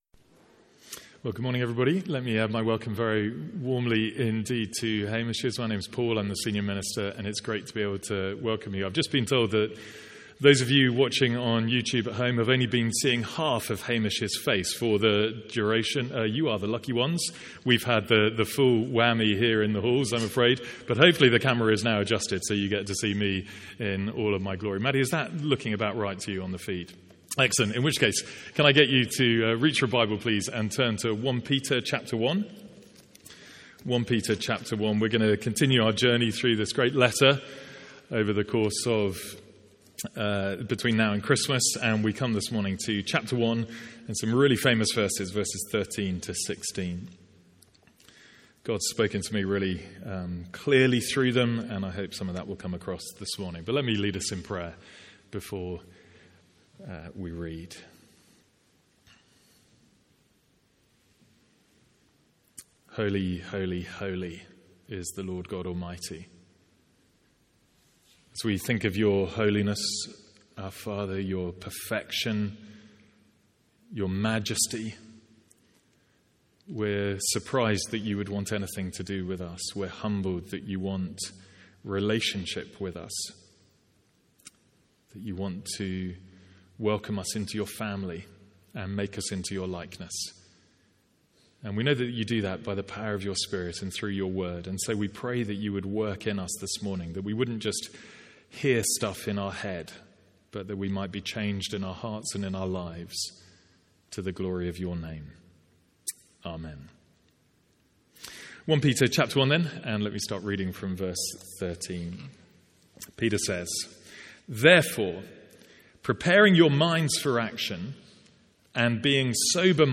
Sermons | St Andrews Free Church
From our morning series in 1 Peter.